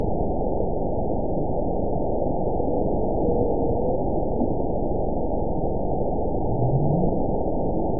event 922022 date 12/25/24 time 11:44:59 GMT (4 months, 1 week ago) score 9.60 location TSS-AB02 detected by nrw target species NRW annotations +NRW Spectrogram: Frequency (kHz) vs. Time (s) audio not available .wav